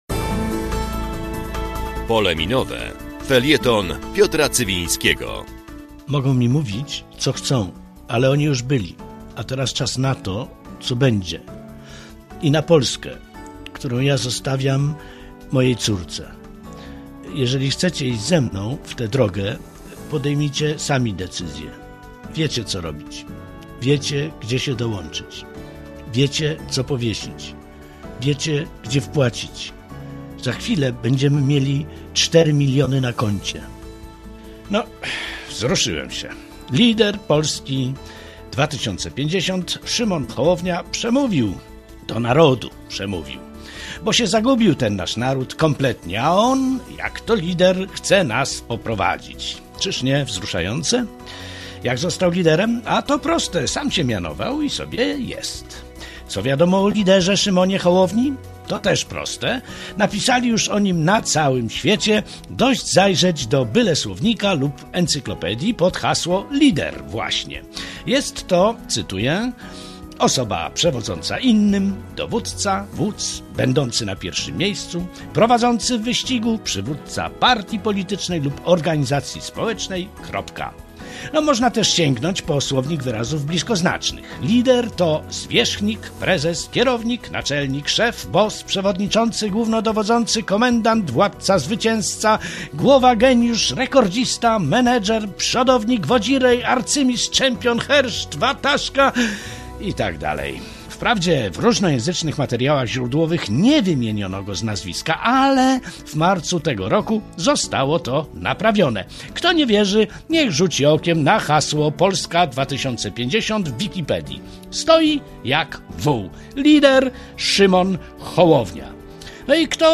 W Radiu Zachód w każdą sobotę po godz. 12:15.
"Pole Minowe" - felieton polityczny